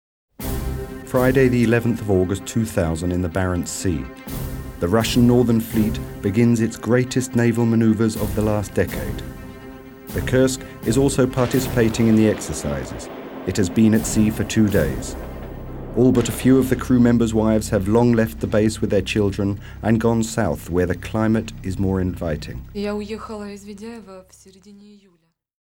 Sprecher englisch uk.
Sprechprobe: Sonstiges (Muttersprache):